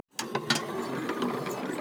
pgs/Assets/Audio/Sci-Fi Sounds/Mechanical/Engine 2 Start.wav at master
Engine 2 Start.wav